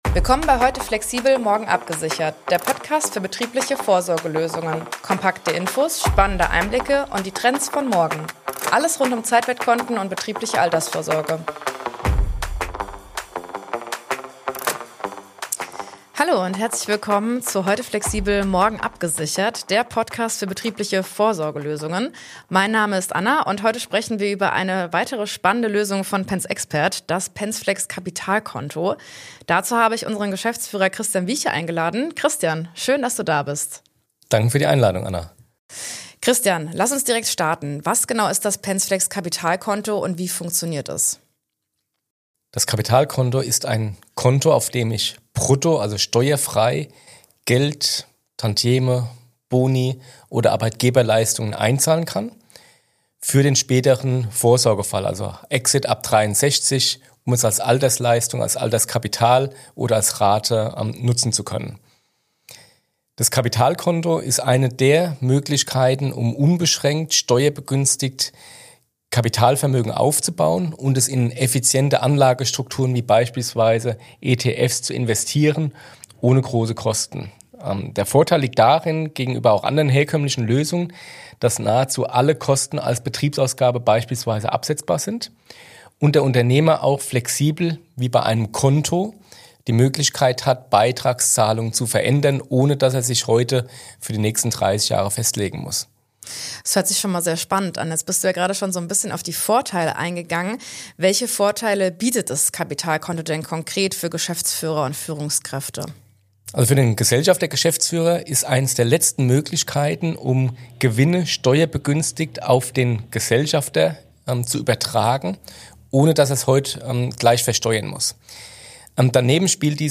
Moderatorin